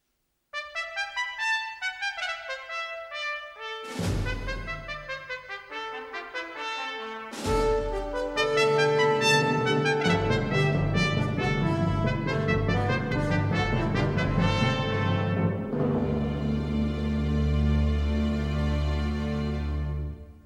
Sintonia instrumental curta.